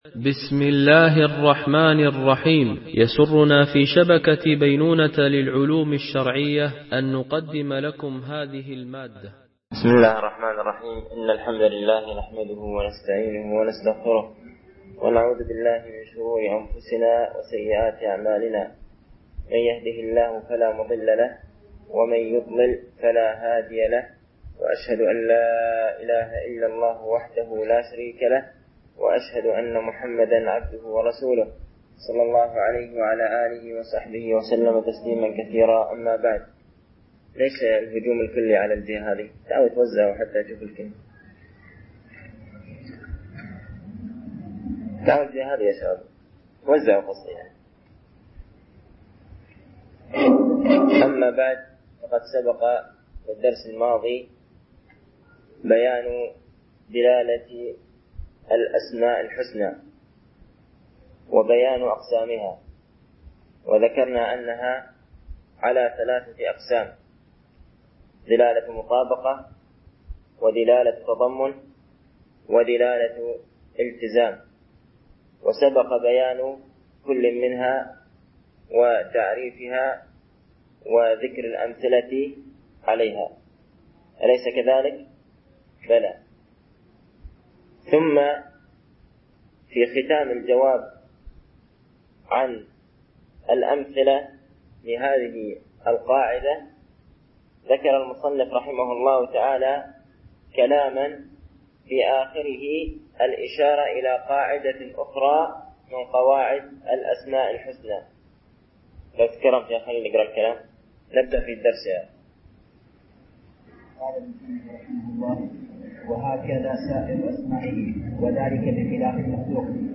MP3 Stereo 22kHz 32Kbps (CBR)